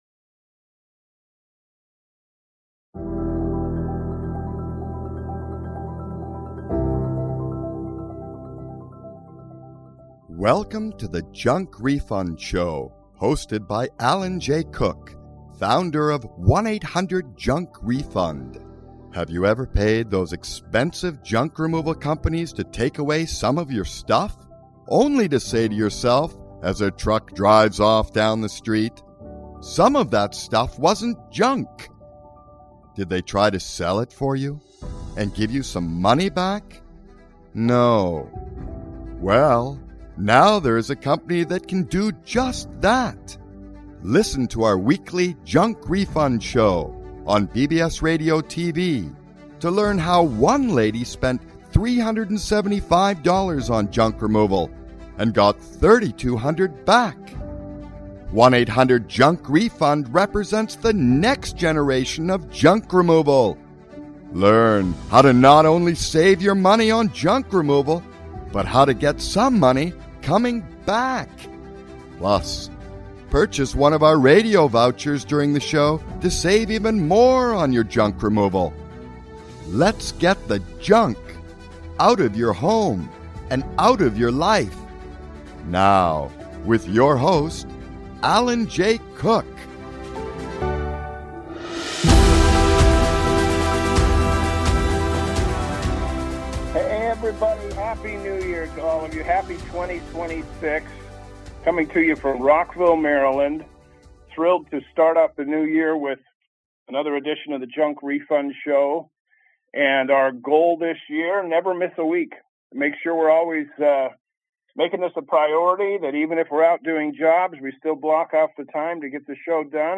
Talk Show Episode, Audio Podcast, Junk Refund Show and Happy New Year, 2026!